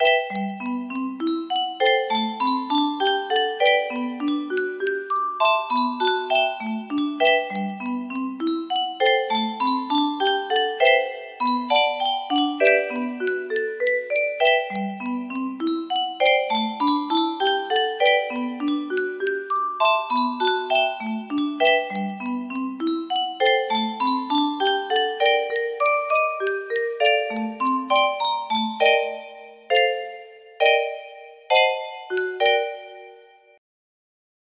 Chorus part only